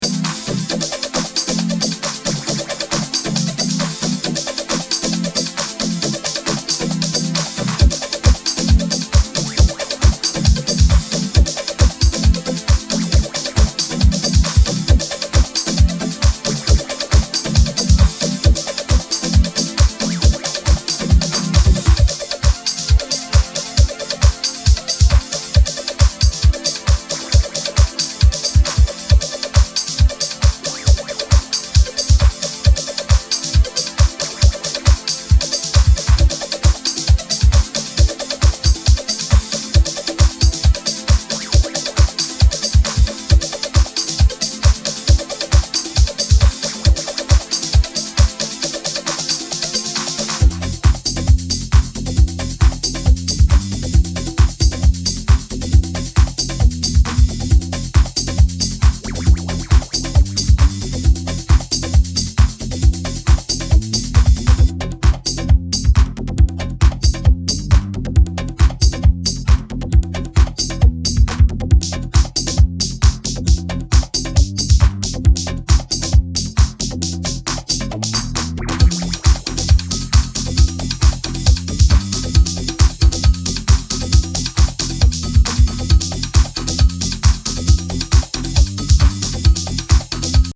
Acid house, Deep house, Garage house, House